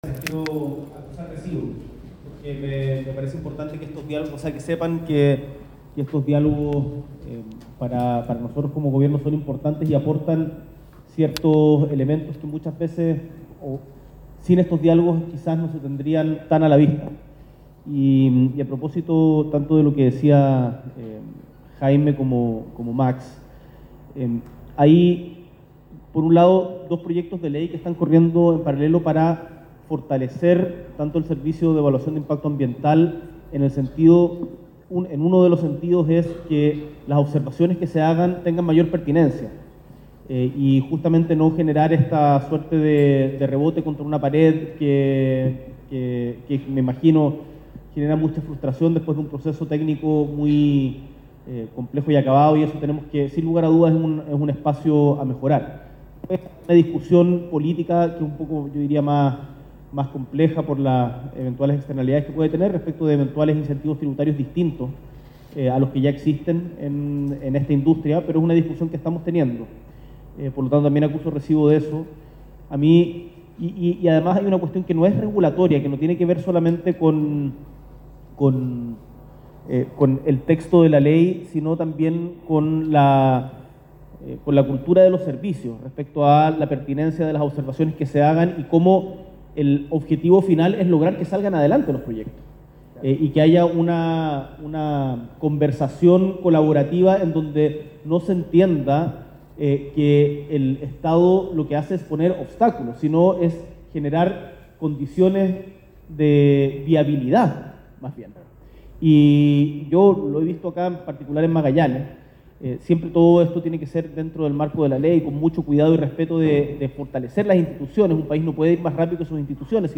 S.E. el Presidente de la República, Gabriel Boric Font, encabeza la ceremonia inaugural del Foro Internacional de Inversiones Punta Arenas 2025: Inversión verde en América Latina y el Caribe, soluciones con alcance global